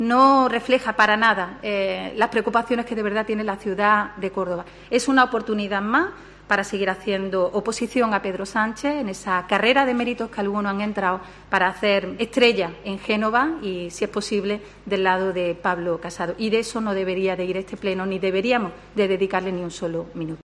Escucha a Isabel Ambrosio, portavoz del Psoe Córdoba en el Ayuntamiento